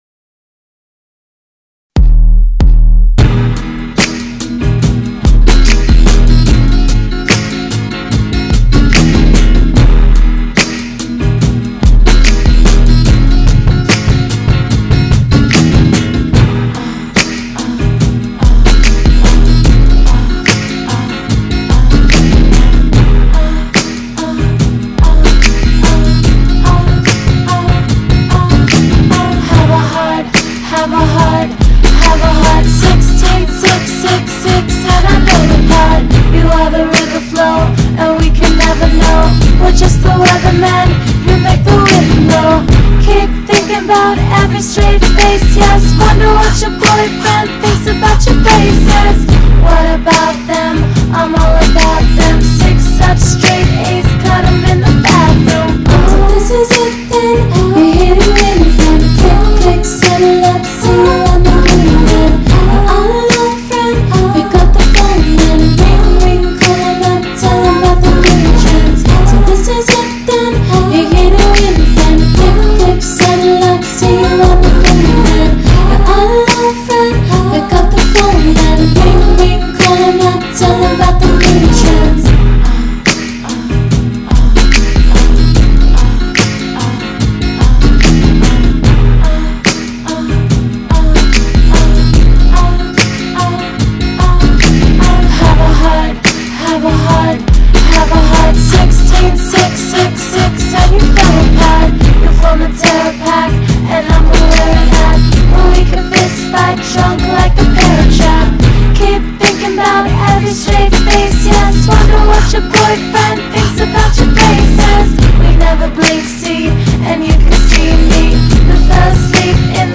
that have infectious finger snaps and solid vocals